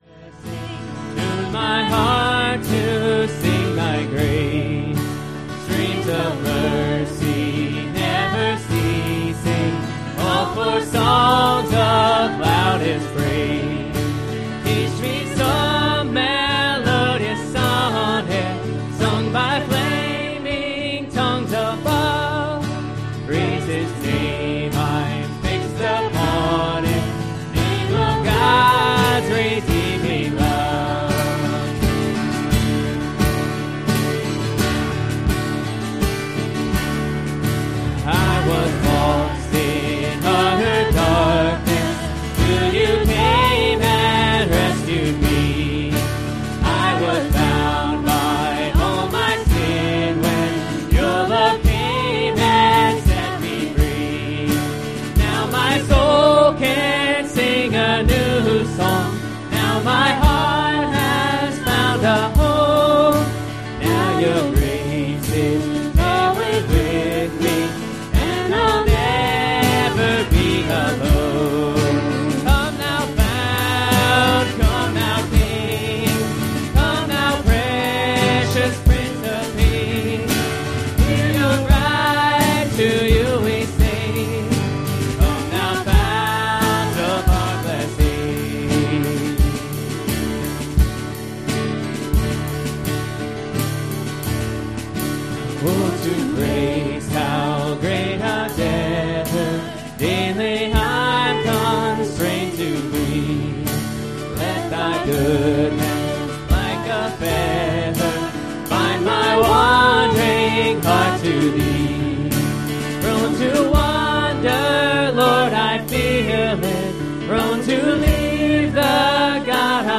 Bicentennial Service